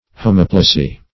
Search Result for " homoplasy" : The Collaborative International Dictionary of English v.0.48: Homoplasy \Ho*mop"la*sy\, n. [Homo- + Gr.